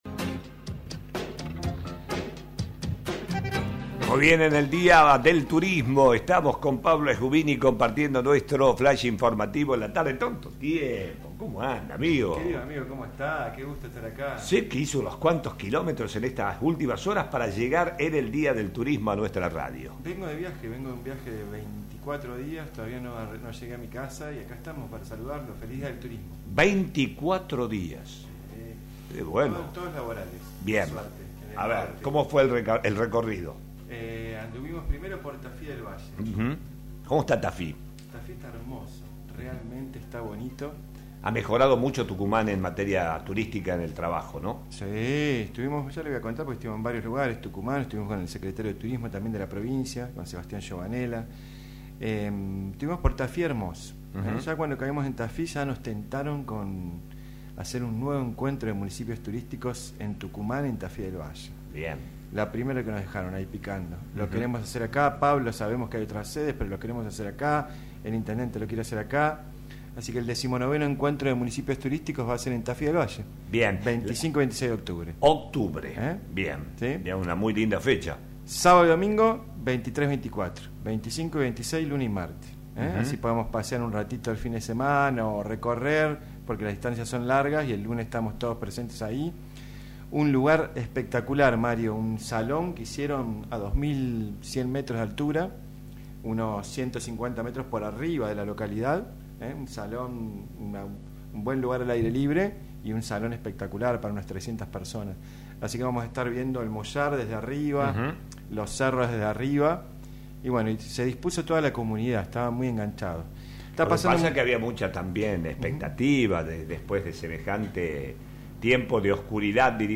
visitó nuestros estudios para hablar de los importantes avances que se vienen logrando con las aperturas al sector, el Pre Viaje y los cuidados que debemos seguir teniendo para asegurarnos una temporada que promete ser exitosa.